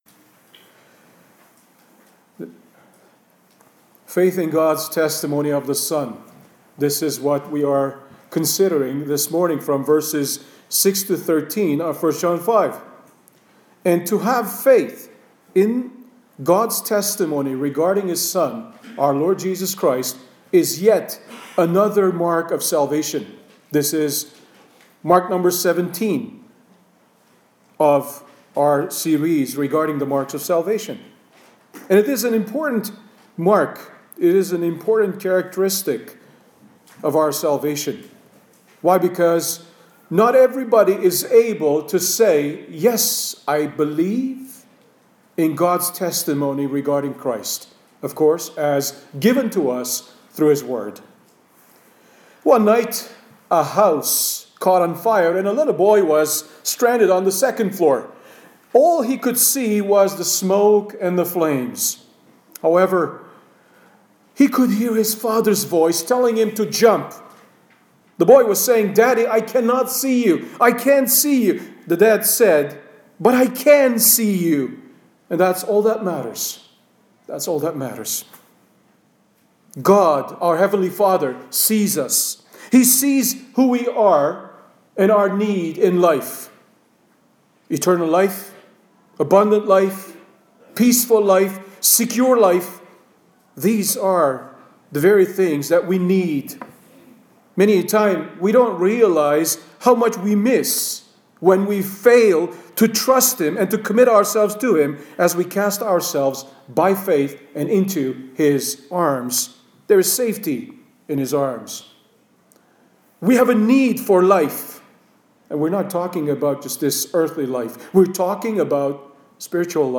Sermons | Evangelical Baptist Church